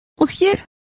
Complete with pronunciation of the translation of doorman.